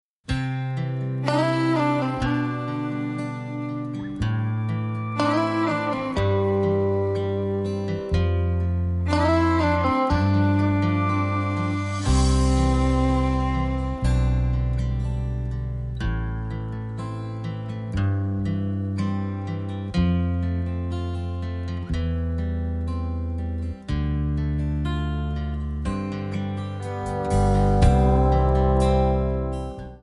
D
MPEG 1 Layer 3 (Stereo)
Backing track Karaoke
Country, 1990s